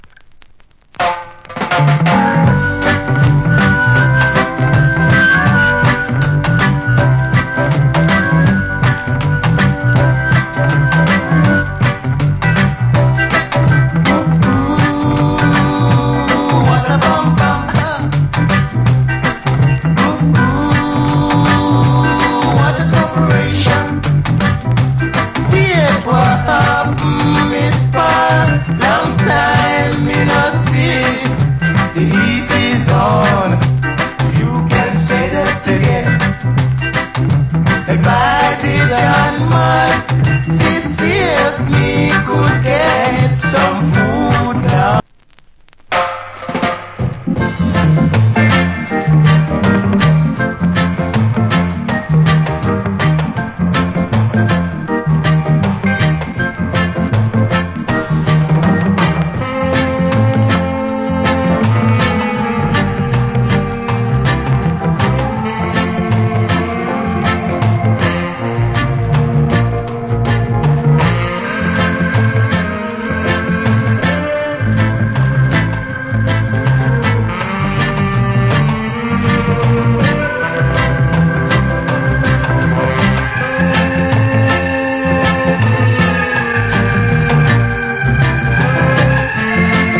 Wicked Rock Steady .